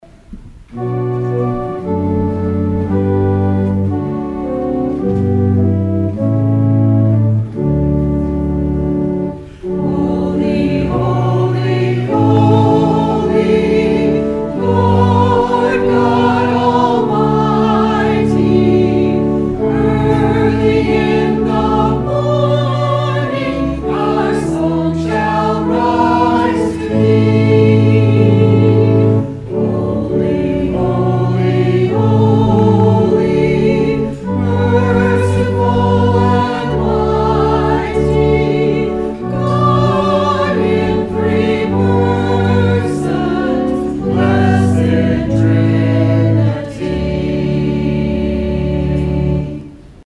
Performed by the Algiers UMC Choir